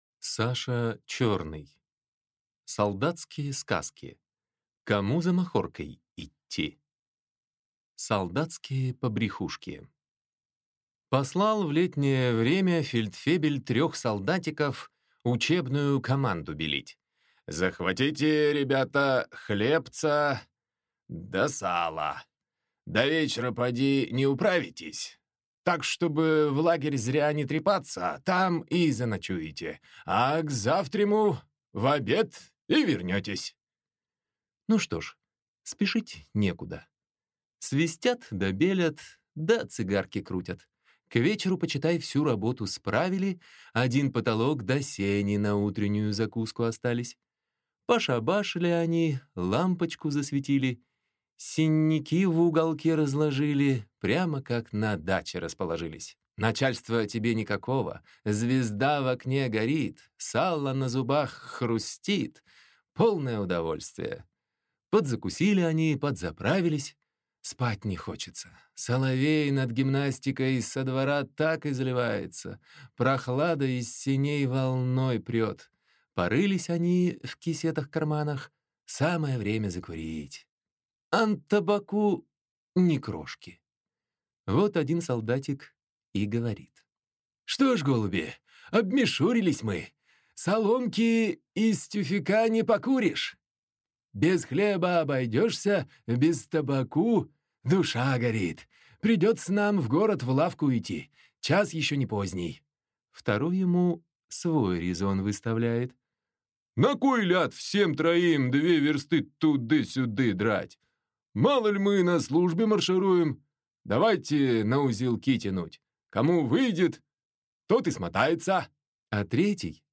Аудиокнига Кому за махоркой идти | Библиотека аудиокниг
Прослушать и бесплатно скачать фрагмент аудиокниги